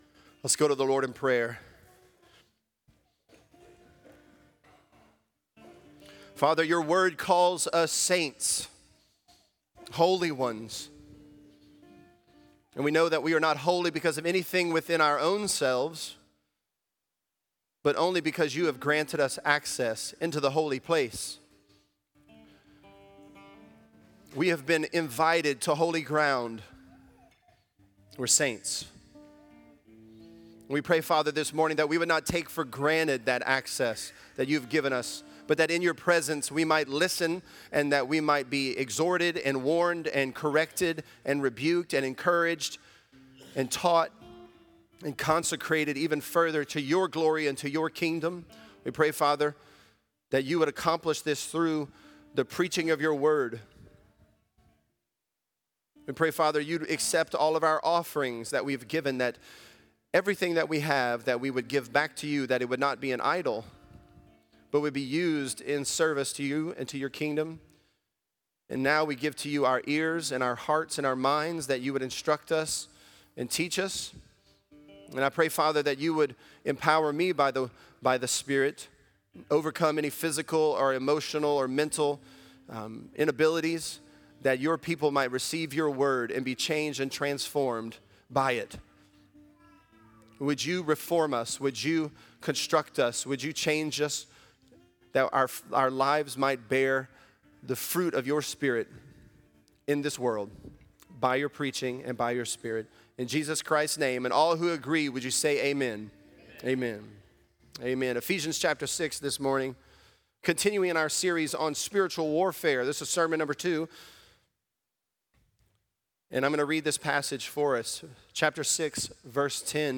Ready For War: We Wrestle | Lafayette - Sermon (Ephesians 6)